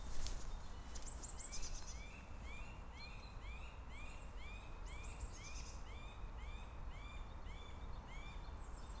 Birds -> Woodpeckers ->
Middle Spotted Woodpecker, Leiopicus medius